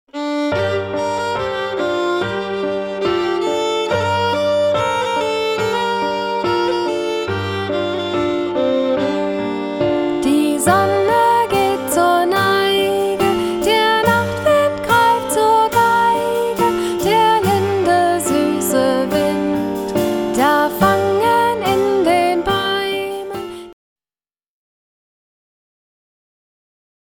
Gattung: Sing- und Sprechkanons für jede Gelegenheit
Besetzung: Gesang Noten